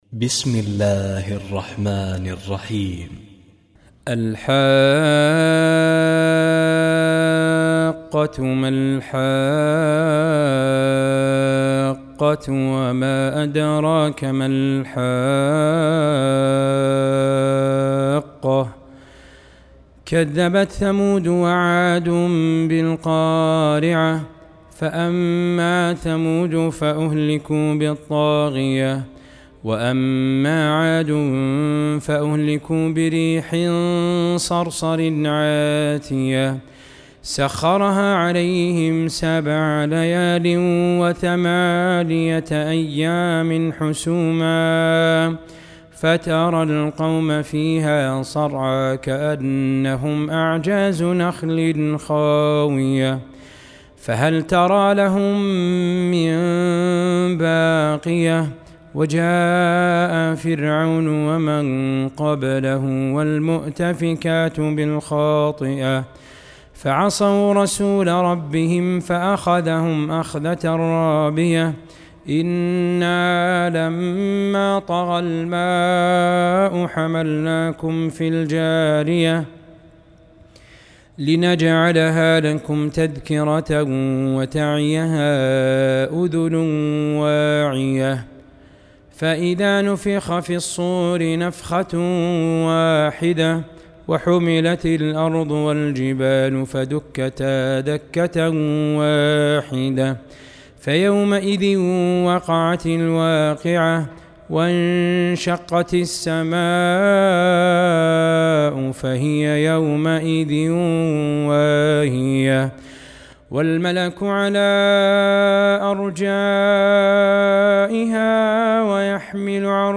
Surah Sequence تتابع السورة Download Surah حمّل السورة Reciting Murattalah Audio for 69. Surah Al-H�qqah سورة الحاقة N.B *Surah Includes Al-Basmalah Reciters Sequents تتابع التلاوات Reciters Repeats تكرار التلاوات